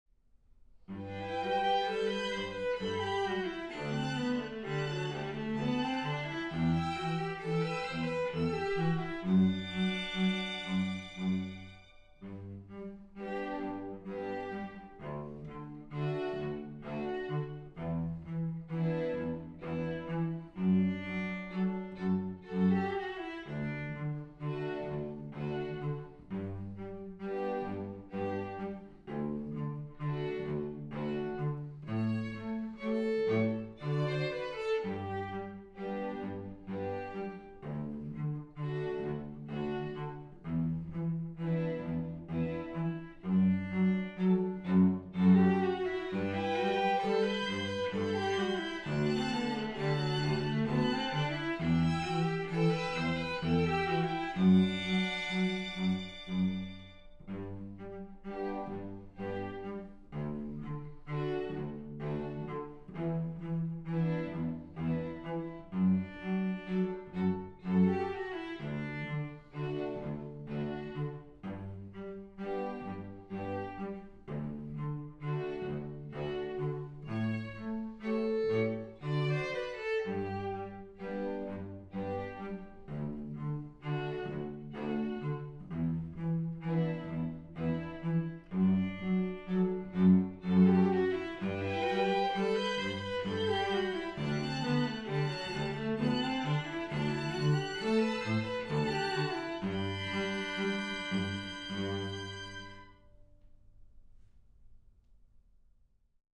recorded accompaniment without Violin or Cello